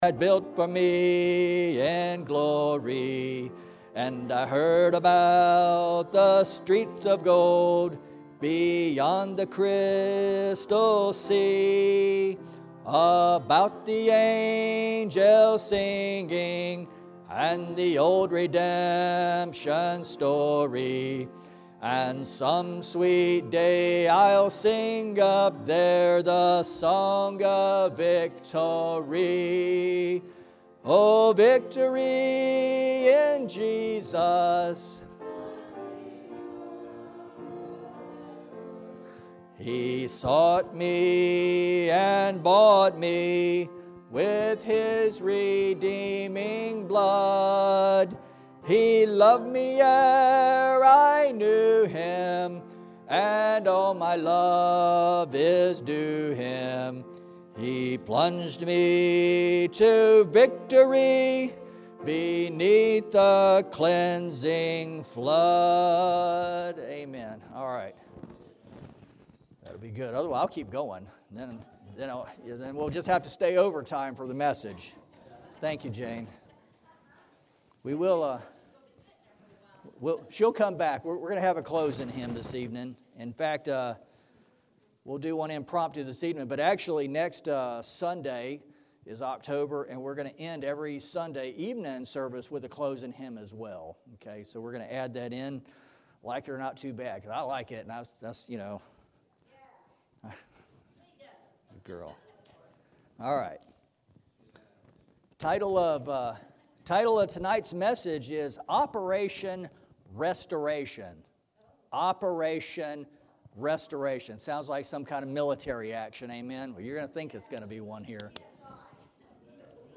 September 27, 2020 Sunday Evening Service Title: “Operation Restoration”